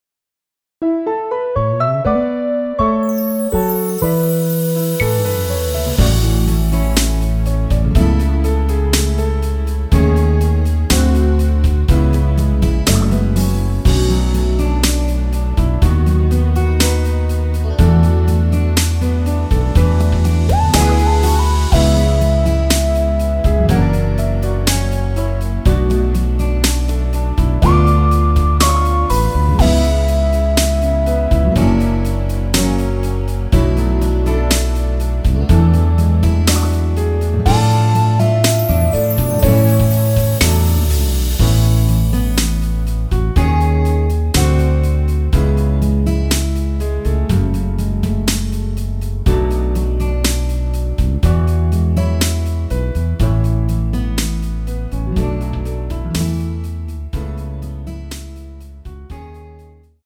원키에서(+1)올린 MR입니다.
앞부분30초, 뒷부분30초씩 편집해서 올려 드리고 있습니다.
중간에 음이 끈어지고 다시 나오는 이유는